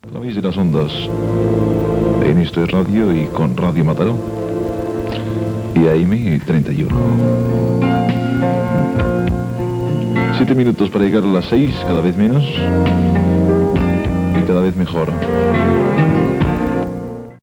7e41b654319ea5009a8ca70a11cd2e5cfa73bc36.mp3 Títol Ràdio Mataró Emissora Ràdio Mataró Titularitat Privada local Nom programa Esto es radio Descripció Identificació i hora.